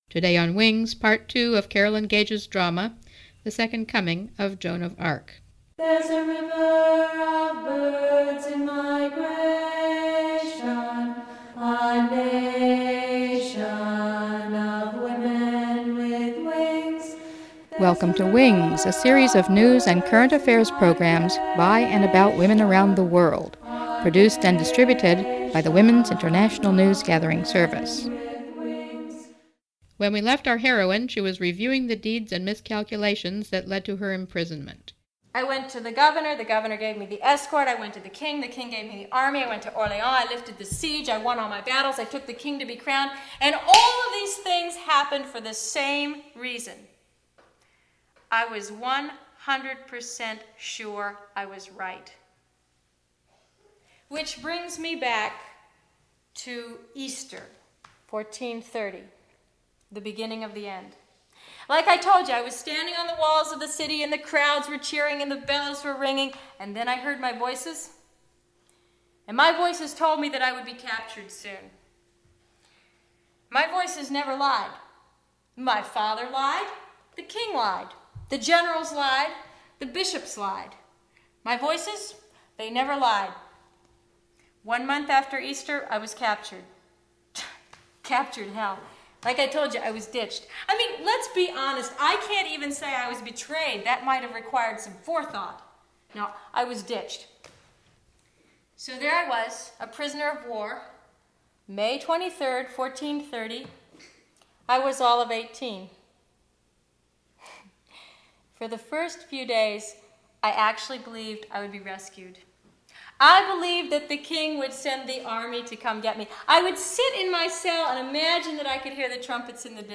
WINGS #08-16, Part 2 of The Second Coming of Joan of Arc - a 3-part radio drama